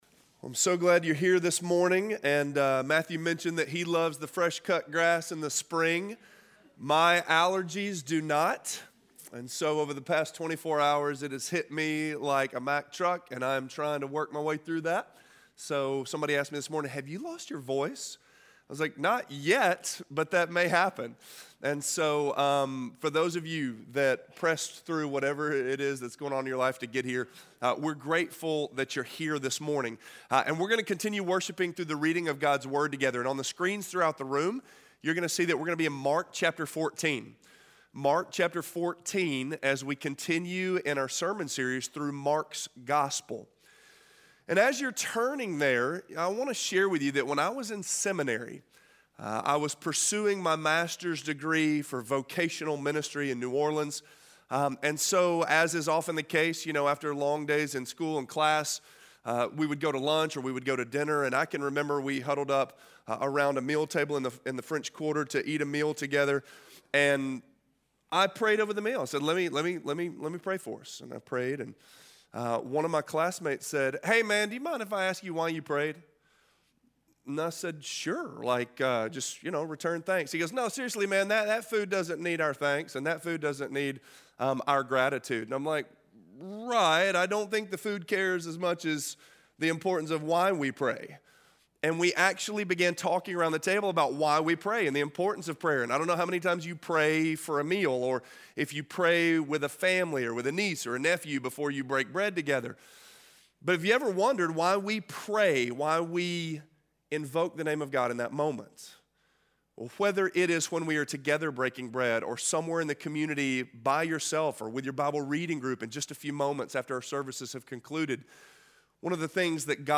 The Feast - Sermon - Avenue South
The_Church_at_Avenue_South___April_7__2019___Sermon_only_audio.mp3